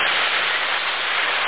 static.mp3